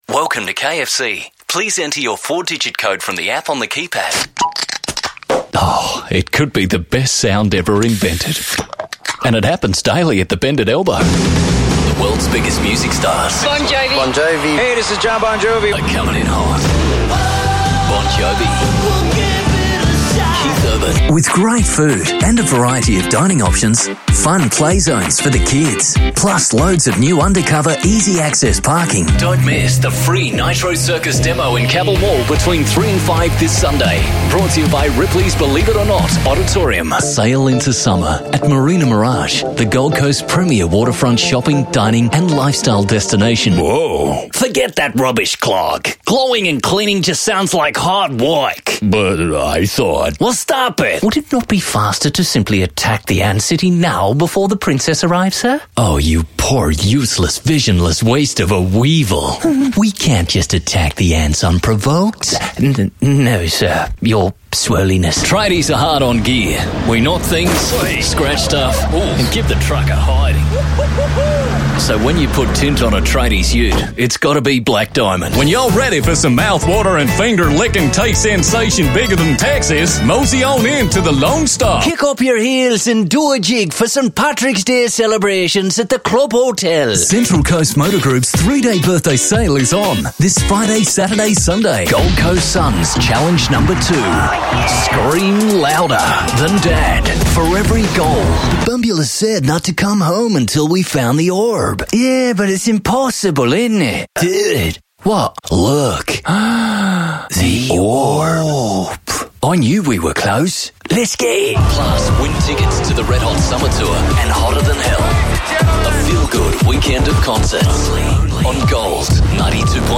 Brisbane/Gold Coast – Home Studio available
Commercial reel